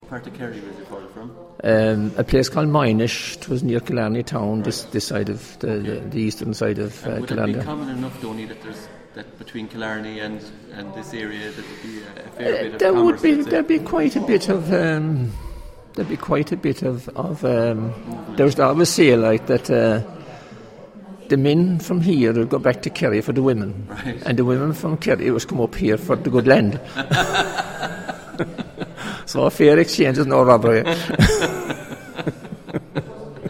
This is my favourite bit of audio from a day spent editing recordings made during the Historic Graves training programme in the Duhallow area of Co. Cork.